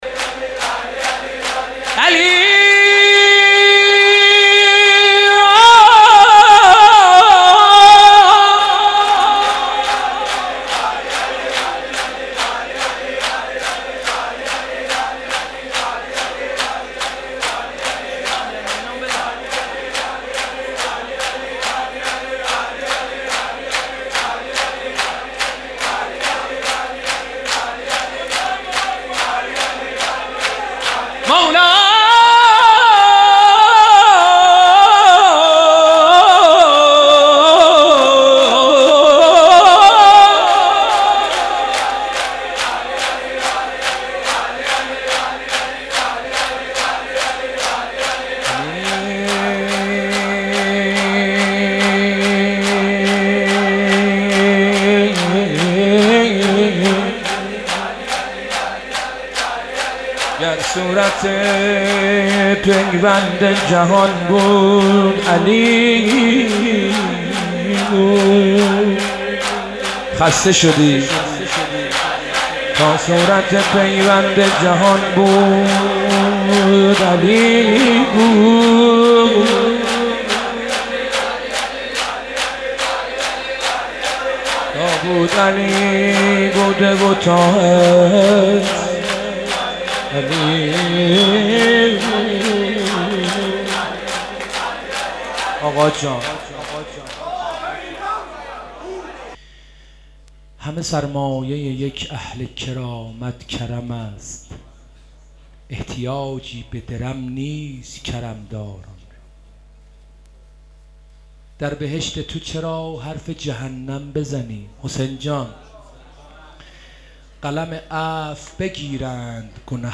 مناسبت : شب پنجم محرم
قالب : مدح